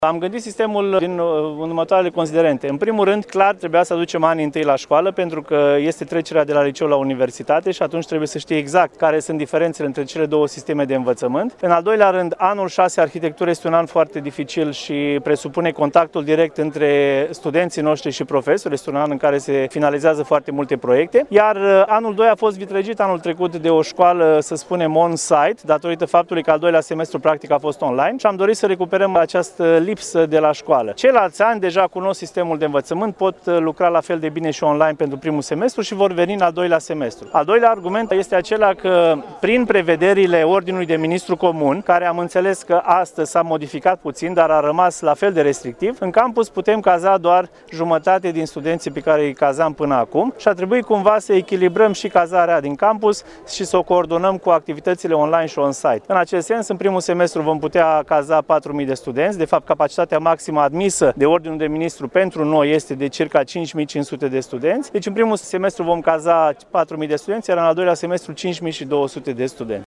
Peste 500 de studenţi şi cadre didactice au participat, astăzi, în aer liber, la deschiderea anului academic la Universitatea Tehnică Gheorghe Asachi din Iași.
Festivitatea a avut loc în campusul studențesc Tudor Vladimirescu.